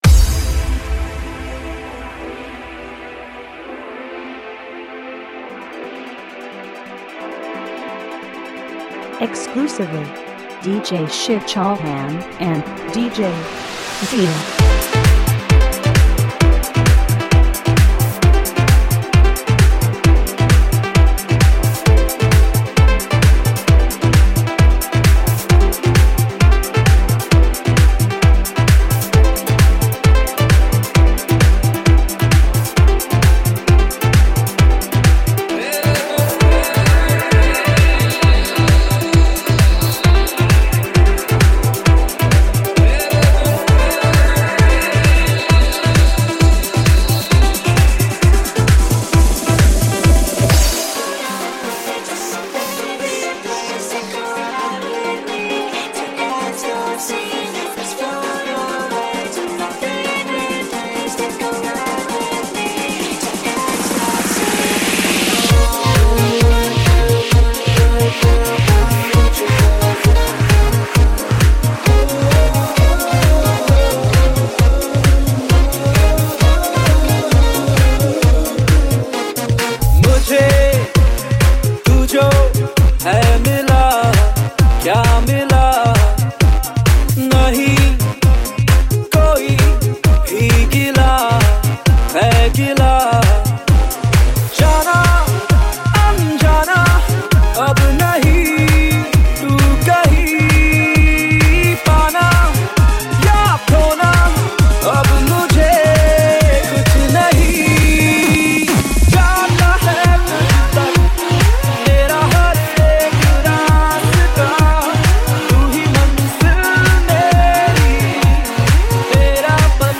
HomeMp3 Audio Songs > Others > Latest DJ-Mixes (March 2013)